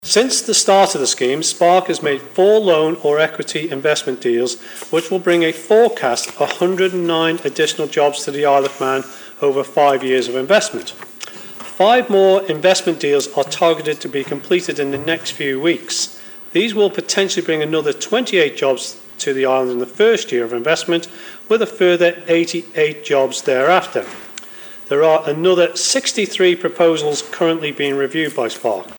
Economic Development Minister Laurence Skelly was asked about a new £50 million investment fund in Tynwald last week.
Mr Skelly revealed how much had been spent so far: